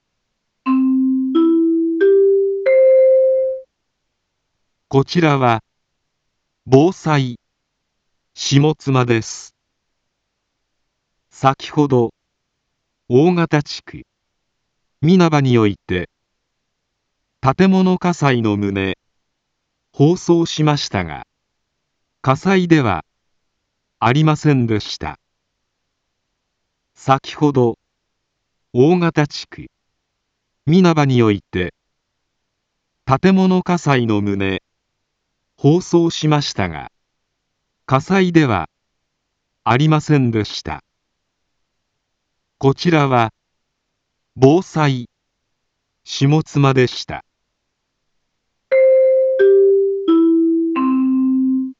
一般放送情報
BO-SAI navi Back Home 一般放送情報 音声放送 再生 一般放送情報 登録日時：2021-12-13 20:57:55 タイトル：誤報について インフォメーション：こちらは、防災下妻です。 先程、大形地区、皆葉において、 建物火災の旨、放送しましたが、火災ではありませんでした。